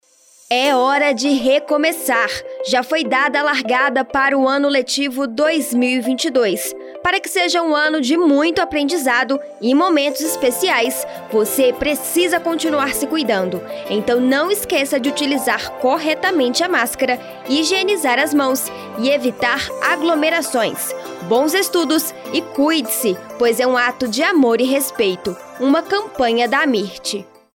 Spot: